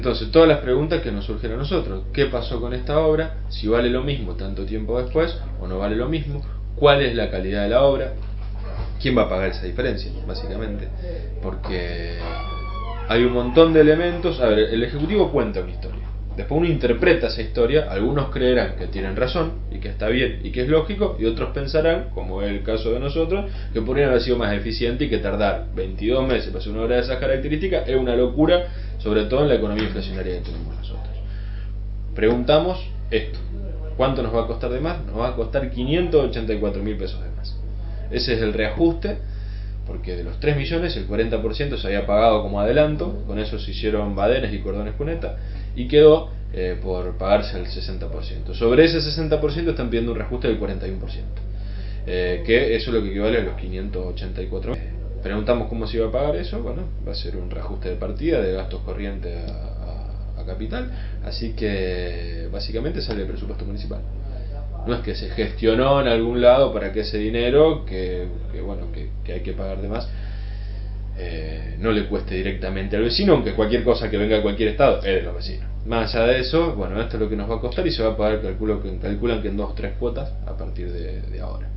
[AUDIO] Firmat24 dialogó con el concejal que impulsó el pedido de informes por las obras de pavimentación en el Área Industrial.